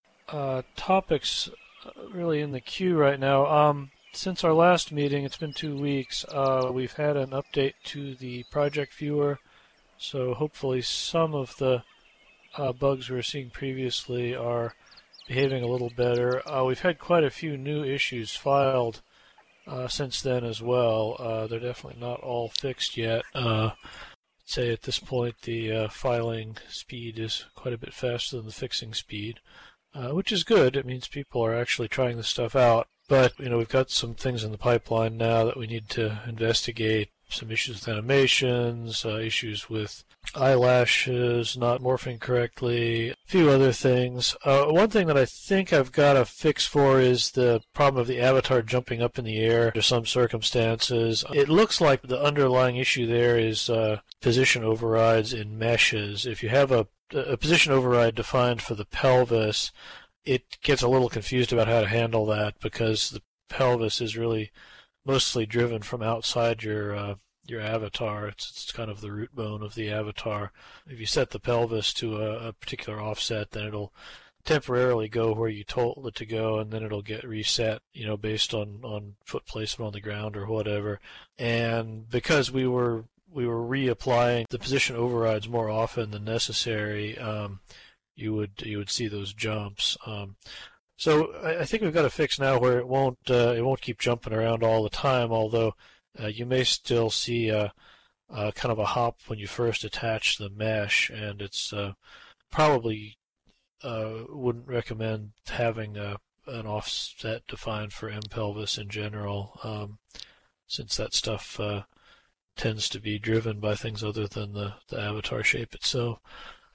The following notes and audio were taken from the weekly Bento User Group meeting, held on Thursday, June 16th at 13:00 SLT at the the Hippotropolis Campfire Circle .